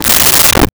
Desk Drawer Open 01
Desk Drawer Open 01.wav